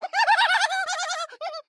*大笑*